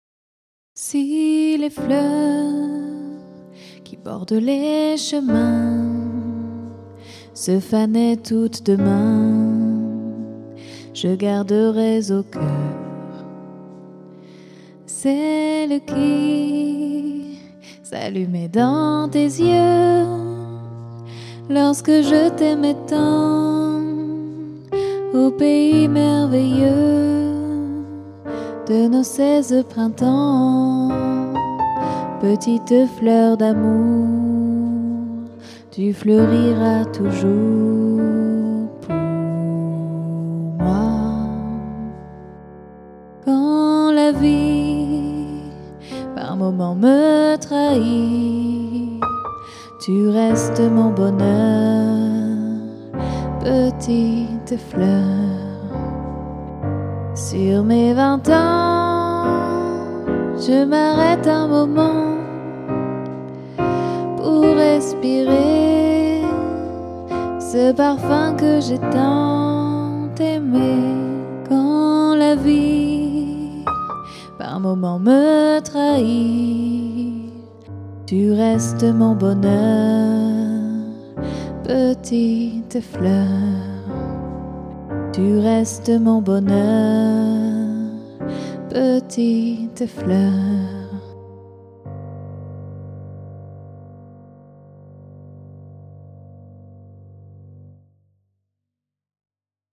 Un duo Piano-Voix au répertoire jazz et variété